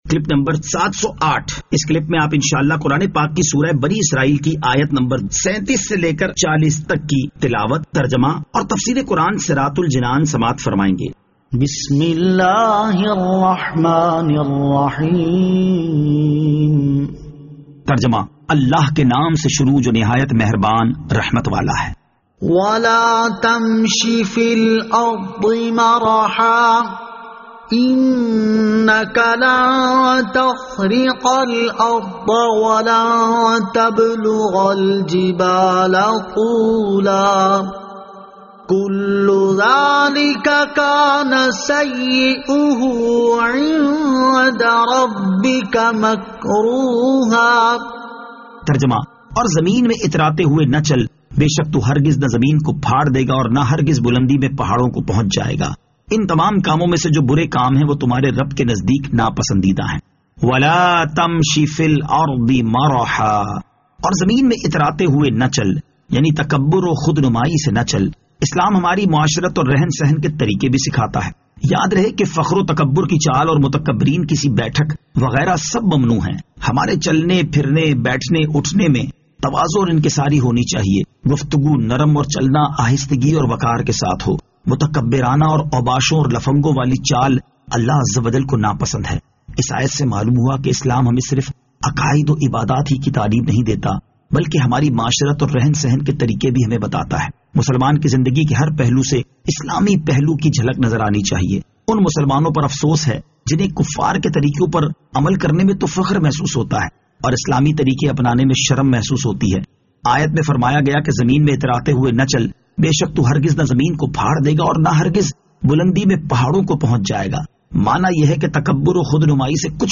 Surah Al-Isra Ayat 37 To 40 Tilawat , Tarjama , Tafseer